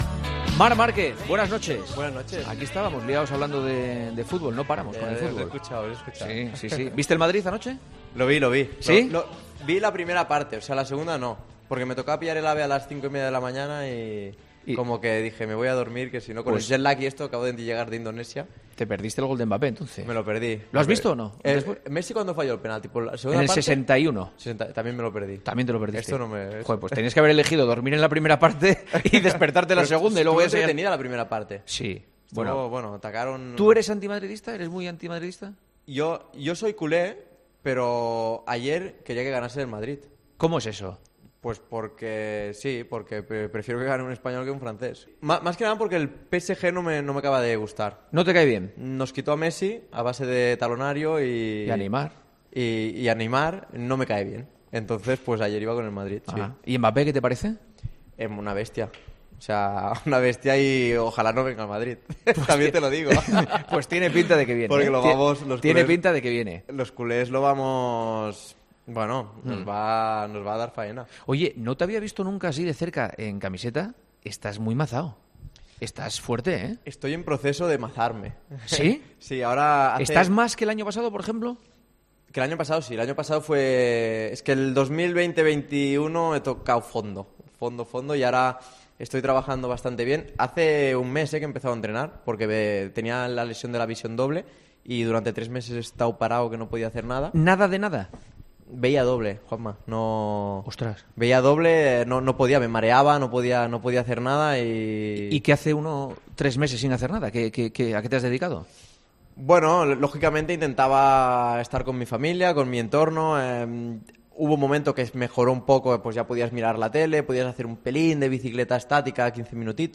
Antes de esa cita, el campeón del mundo, Marc Márquez, ha querido estar en El Partidazo de COPE con Juanma Castaño para hablar sobre cómo se encuentra físicamente tras las lesiones que le han impedido rendir al máximo en los últimos años.